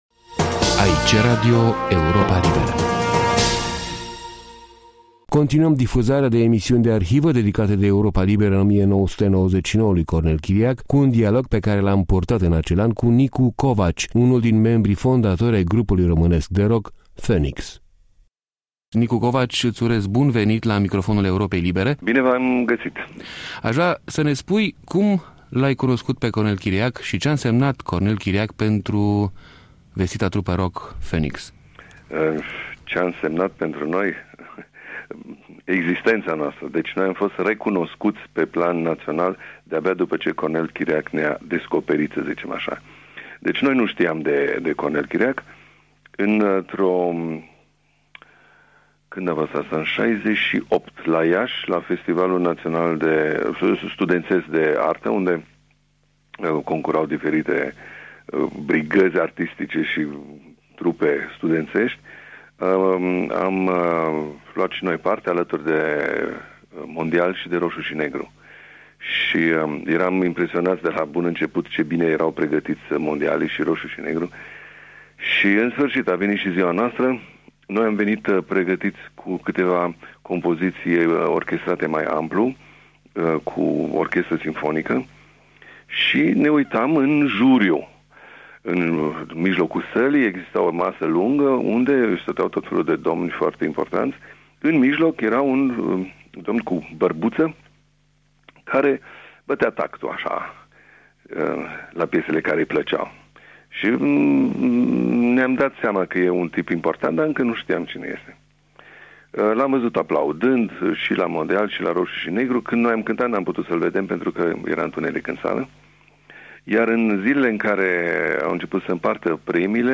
Interviul cu Nicu Covaci din seria comemorativă „Cornel Chiriac” 1999-2000 e urmat de emisiunea Metronom din 18 ianuarie 1975 dedicată trupei Baker Gurvitz Army,.